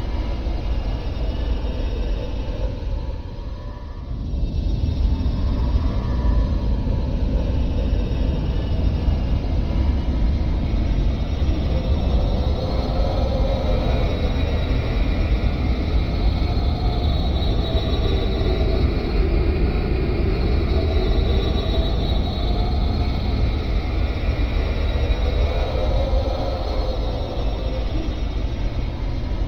ambiances
space.wav